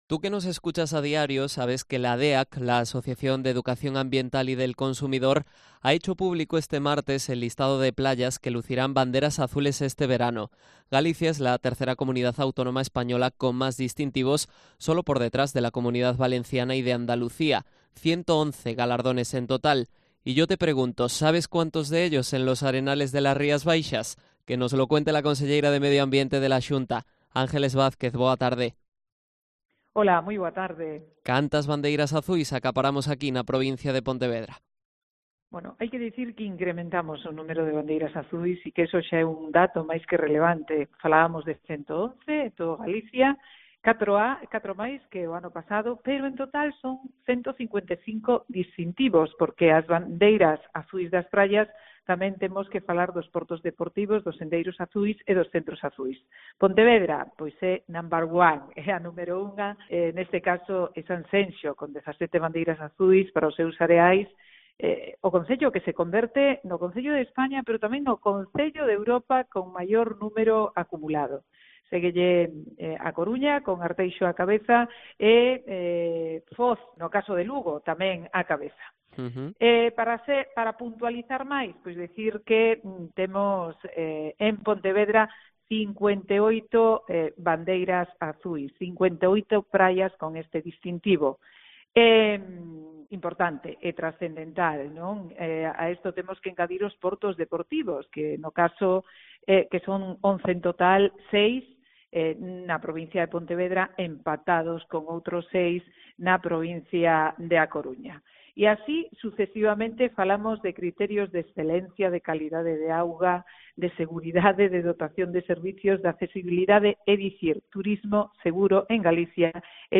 Entrevista a Ángeles Vázquez, conselleira de Medio Ambiente